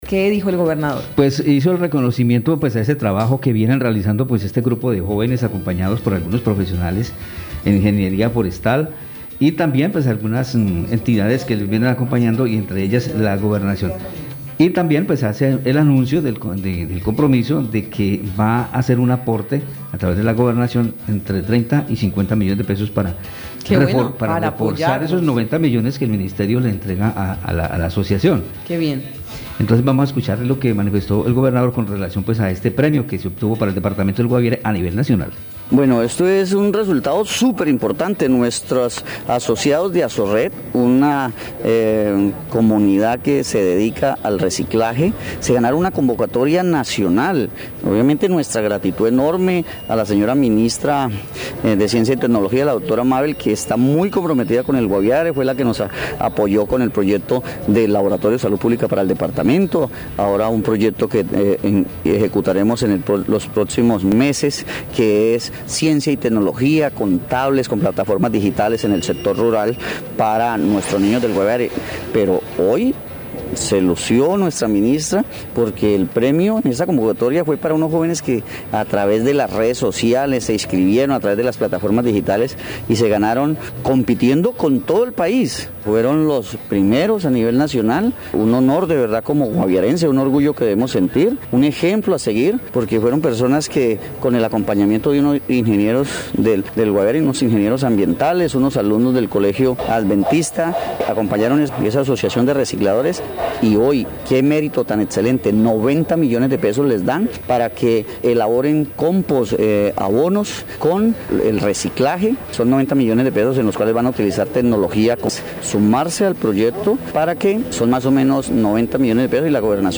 Escuche a Heydeer Palacio, gobernador del Guaviare.